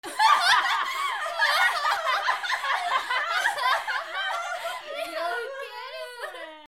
77 笑い声 女性 複数
/ M｜他分類 / L50 ｜ボイス / 球場ガヤ素材_2017_0806
mix